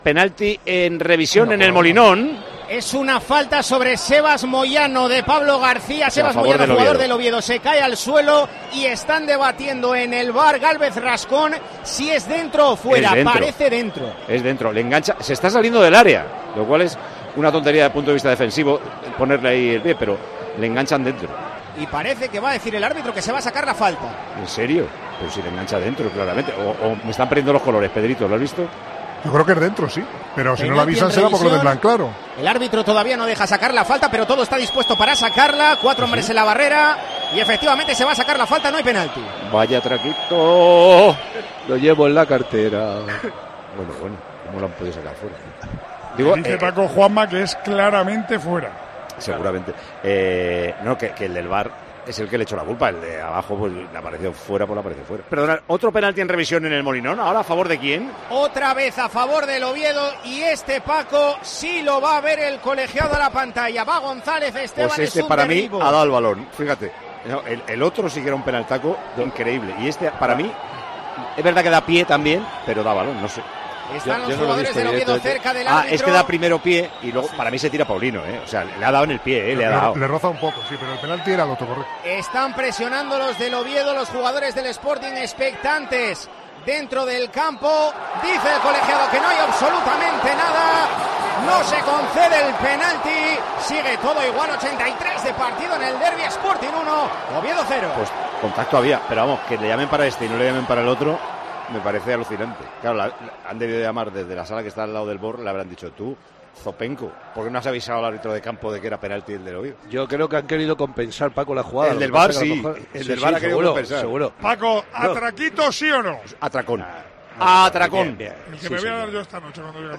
Paco González mostró su incredulidad durante la retransmisión sobre la acción polémica de Pablo García con Sebas Moyano: "¡Cómo lo han podido sacar fuera!"
ESCUCHA LA REACCIÓN DE PACO GONZÁLEZ, MANOLO LAMA Y PEDRO MARTÍN SOBRE LA POLÉMICA EN EL DERBI ASTURIANO